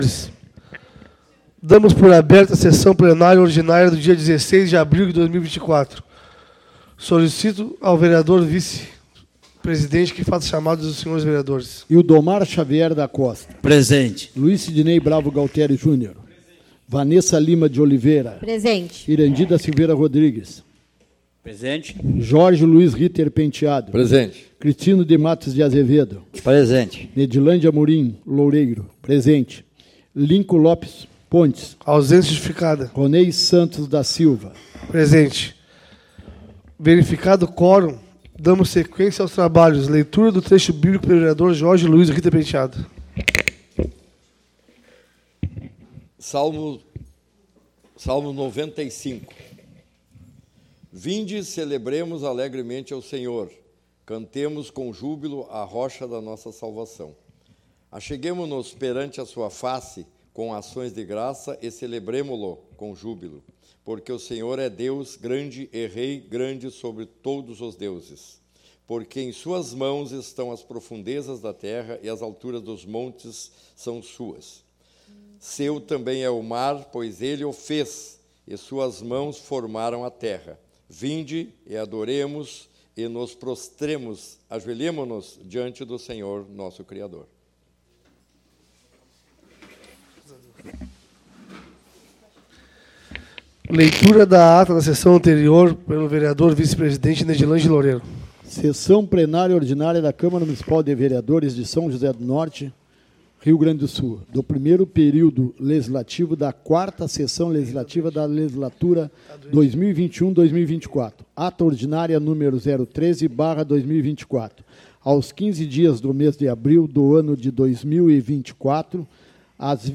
ÁUDIO DA SESSÃO ORDINÁRIA DO DIA 16/04/2024